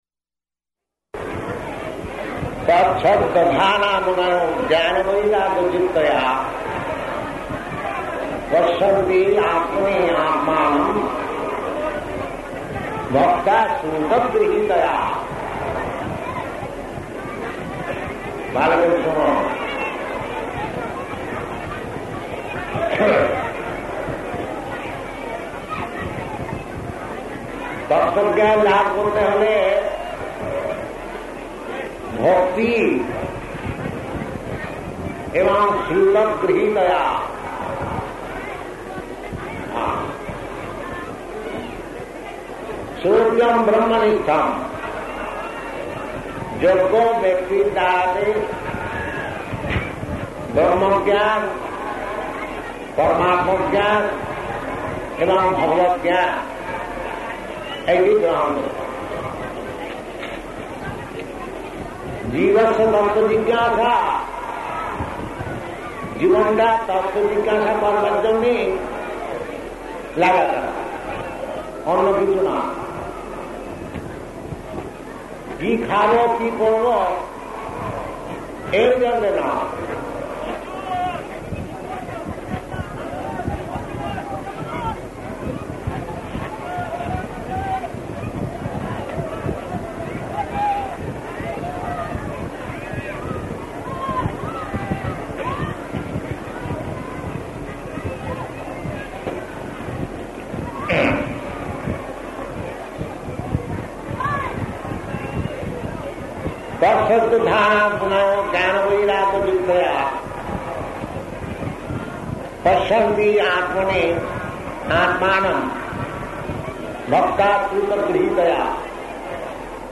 Lecture--Bengali
Type: Lectures and Addresses
Location: Calcutta